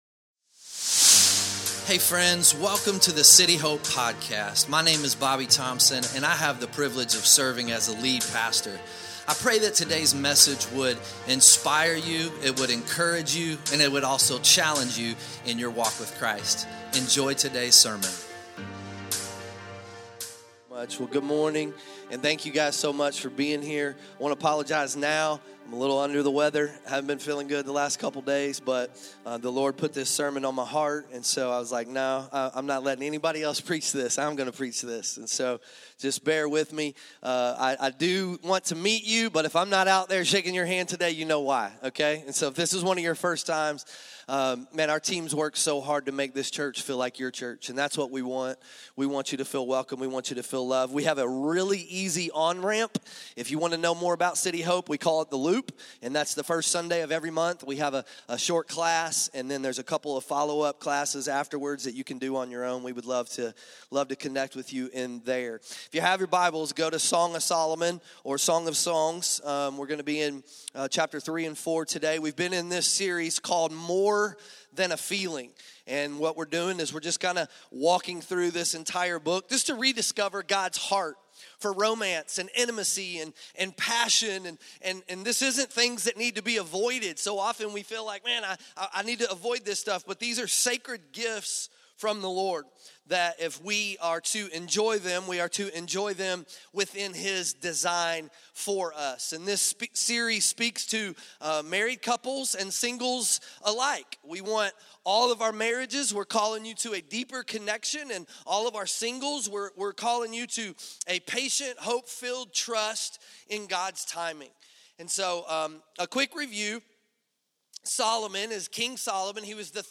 2026 Sunday Morning Culture says test drive everything.